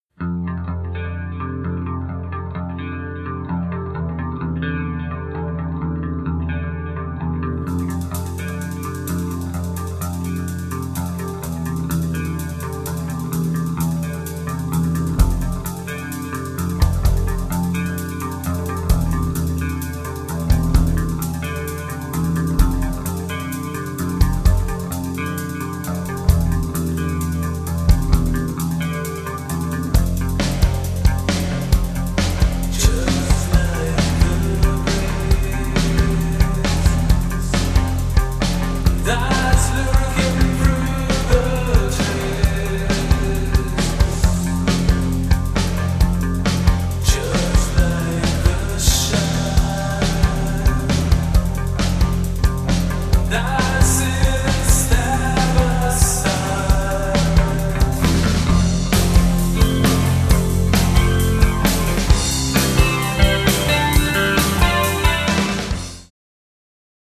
Black Gothic Metal
Recorded at Casablanca Studio, Lambaré, in 1999